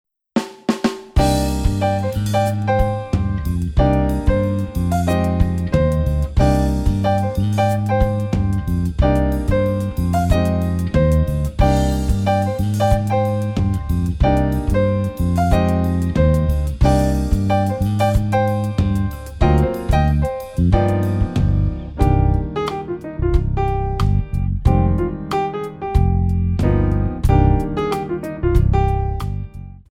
Modern / Contemporary
8 bar intro
moderato